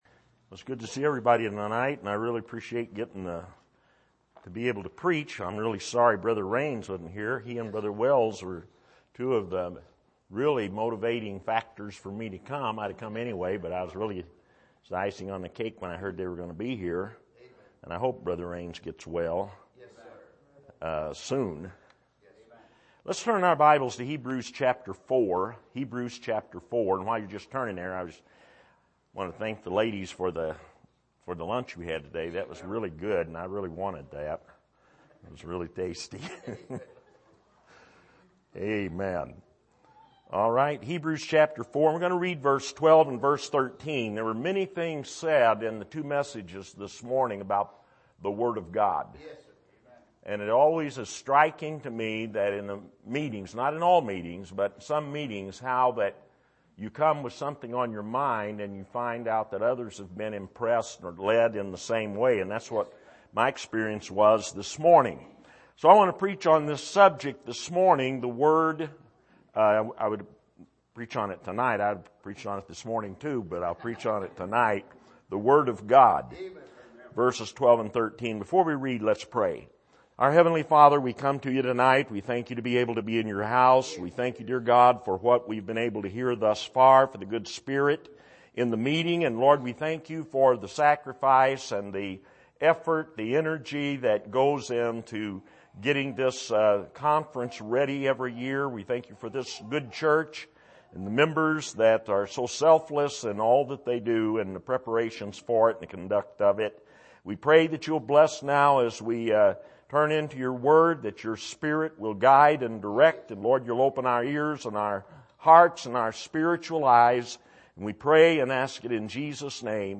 Passage: Hebrews 4:12-13 Service: Sunday Evening